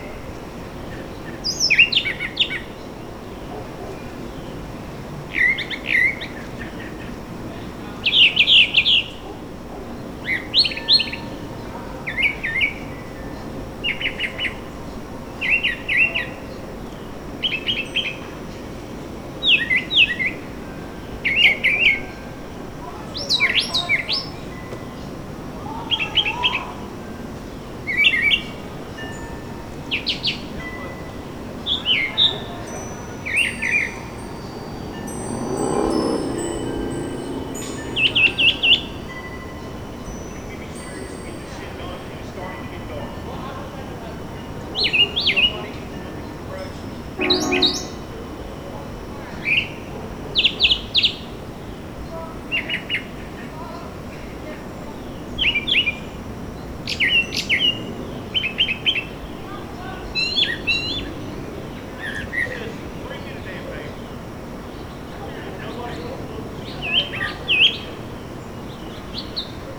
Here’s a recording of one singing.
Listen carefully and you’ll actually hear the bird sing something like “chirpa chirpa” 5 seconds in. You’ll also hear a dog, some random talk, a revving engine, and a train whistle.
I like the thrasher recording because you can easily hear the bird’s trademark paired phrases. I also like it because the bird is singing amid human activity.
brown-thrasher.wav